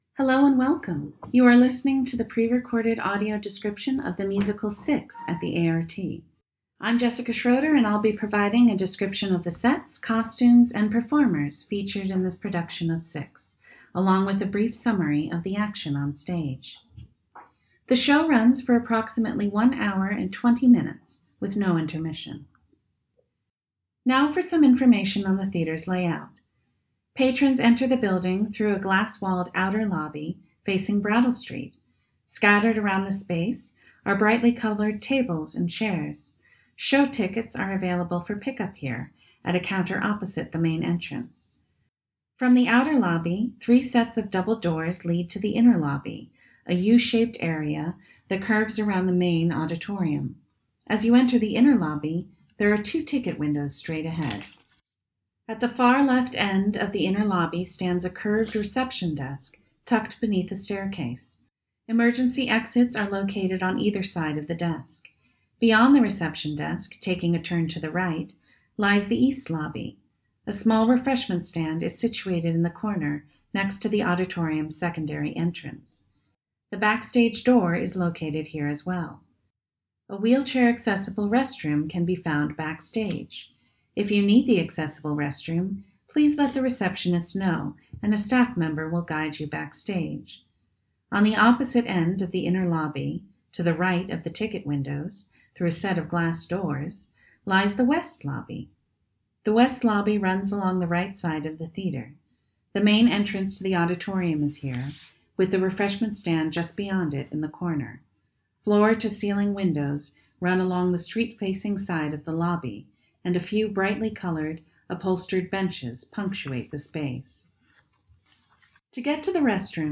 There will be no Audio Described performance, but a pre-show audio description recording is available.
SIX-Audio-Description-FINAL.m4a